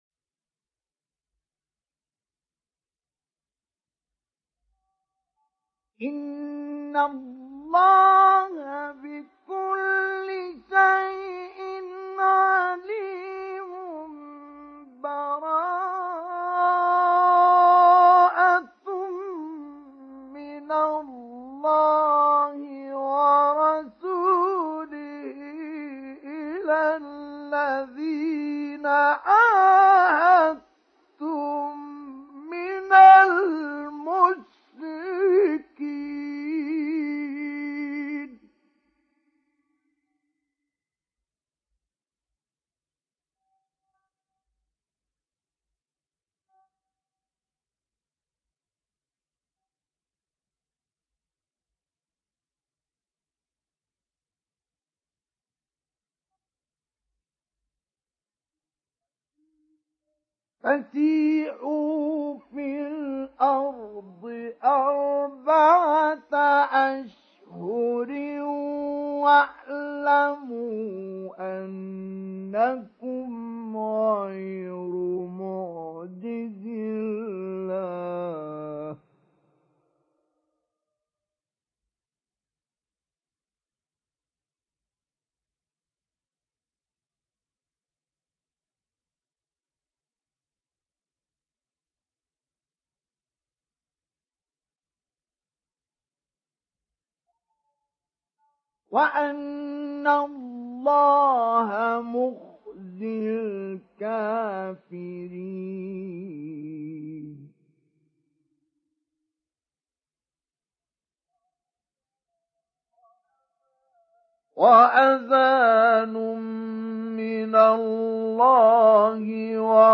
ডাউনলোড সূরা আত-তাওবাহ্ Mustafa Ismail Mujawwad